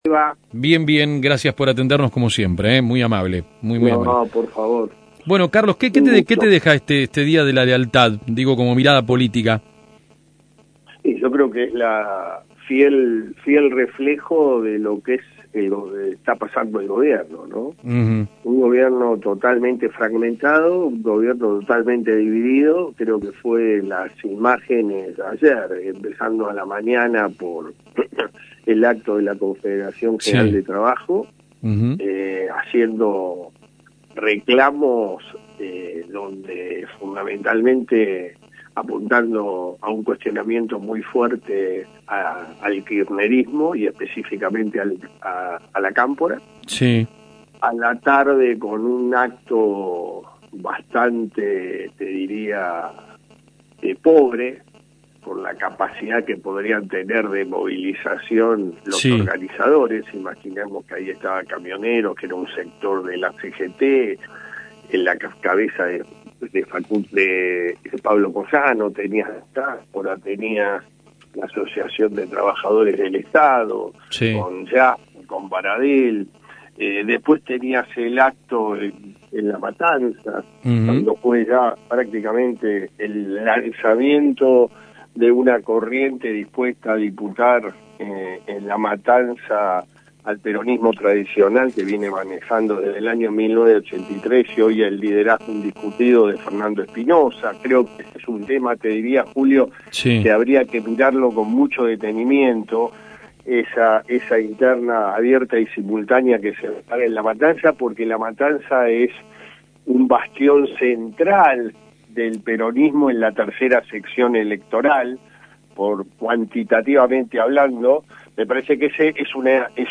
El analista político pasó por am 1210 realizó una lectura de la realidad política